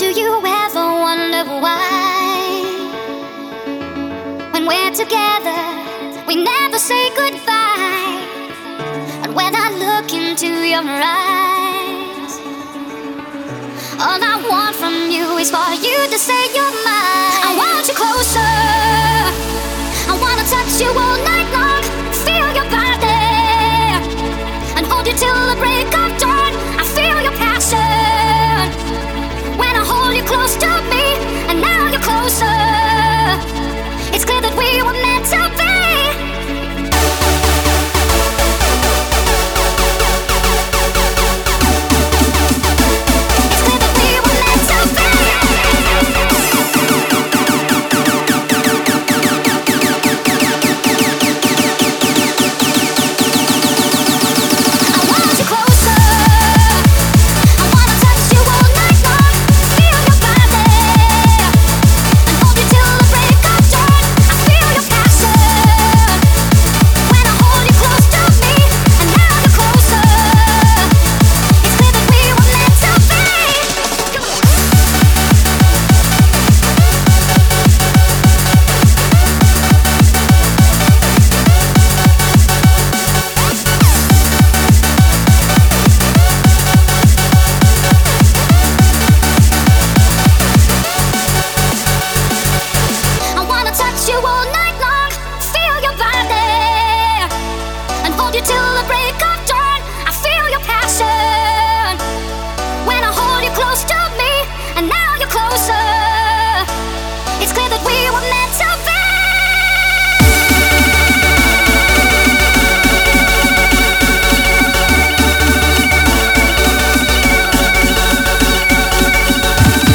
BPM204--1